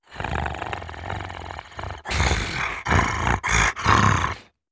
Monster Roars
17. Bloodthirsty Growl.wav